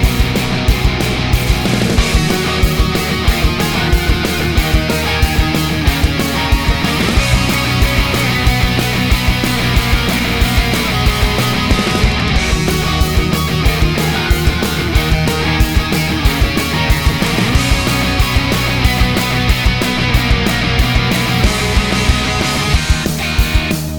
no Backing Vocals Indie / Alternative 3:09 Buy £1.50